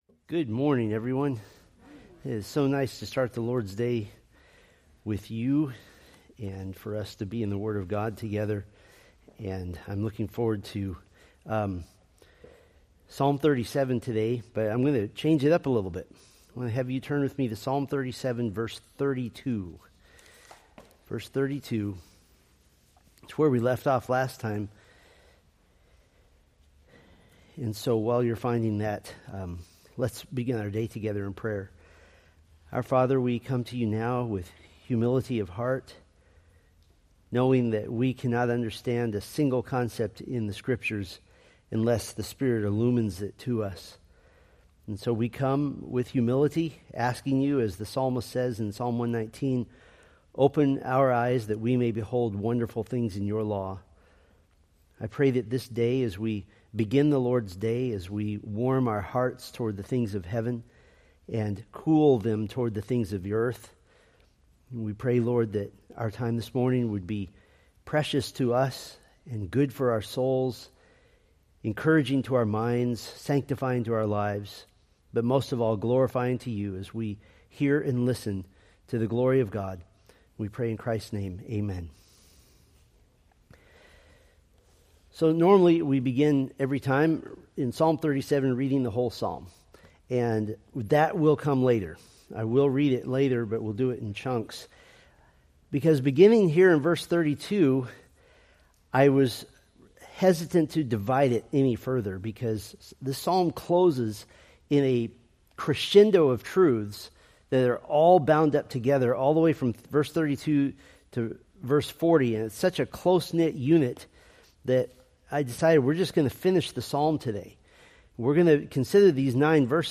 Date: Jul 6, 2025 Series: Psalms Grouping: Sunday School (Adult) More: Download MP3 | YouTube